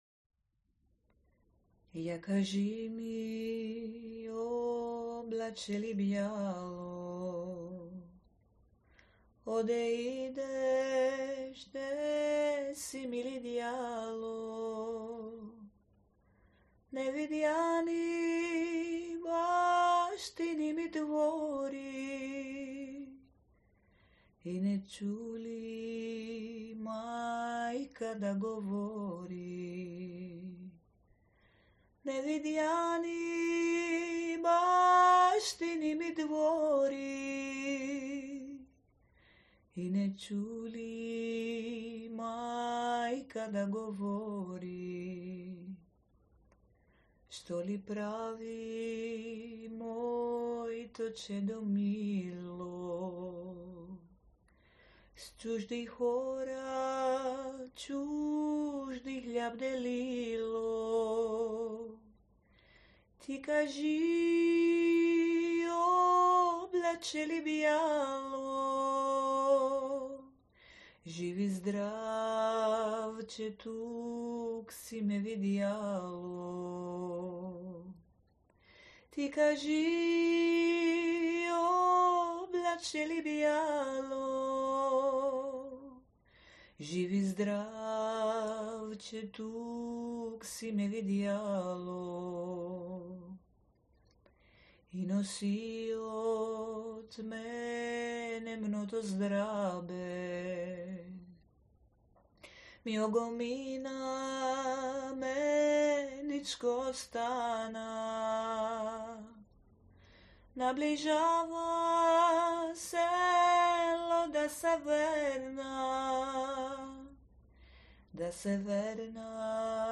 Popular Bulgarian song written in 1916 by Gencho Negentsov
Bulgaria Bulgarian folk song homesongs song